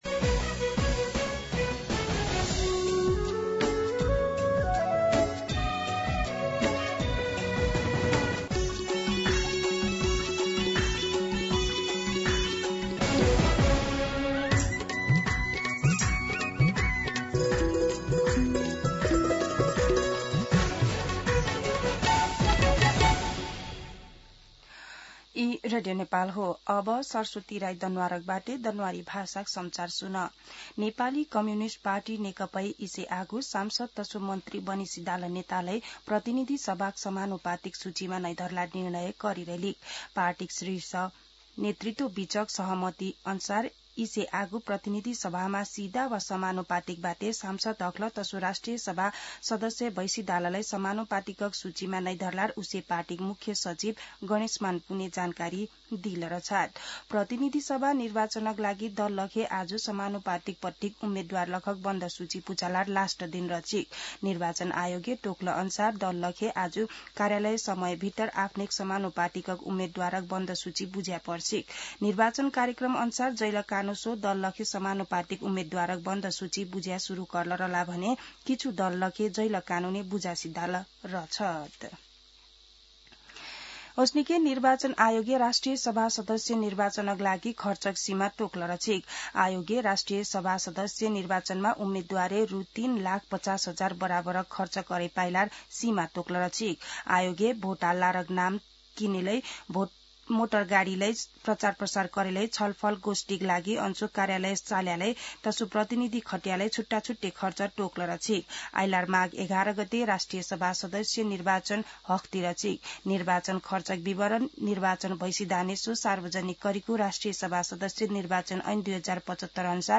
दनुवार भाषामा समाचार : १४ पुष , २०८२
Danuwar-News-9-14.mp3